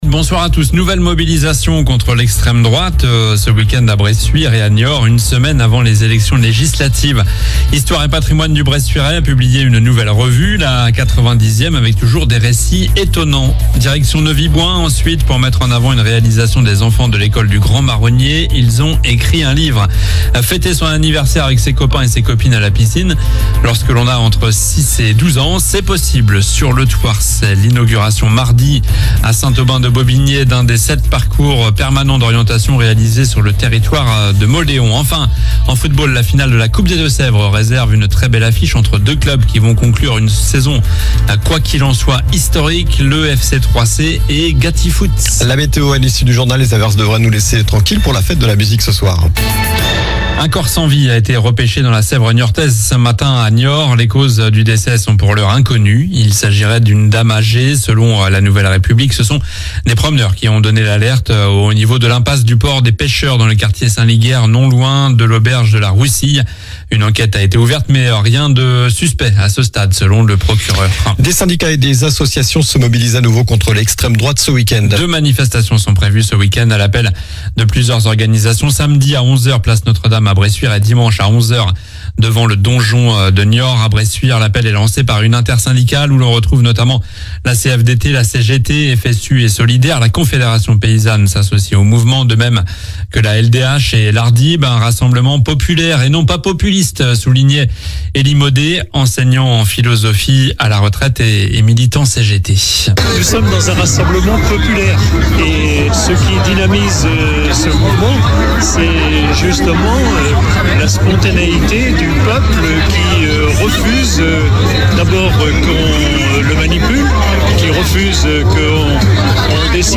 Journal du vendredi 21 juin (soir)